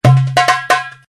Index of /phonetones/unzipped/Sony Ericsson/Xperia-X10/notifications
darbuka.ogg